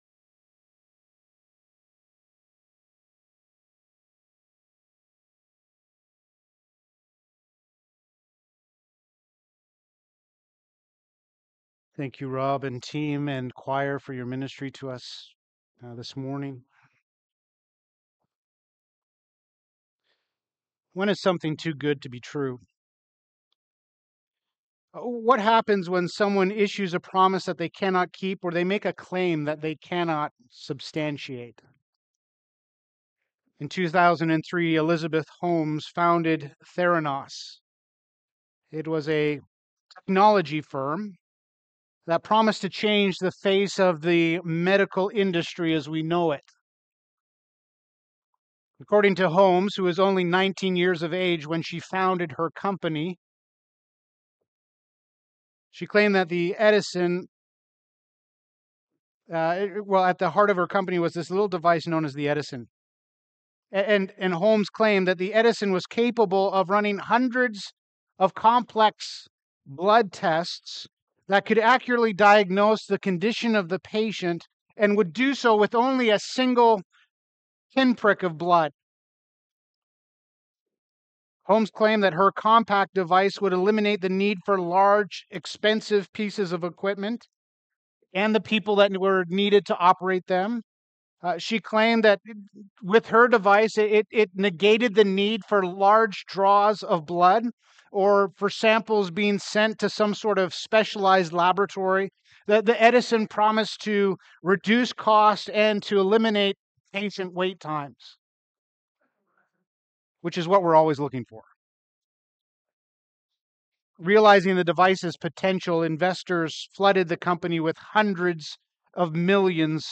Sunday Sermon Series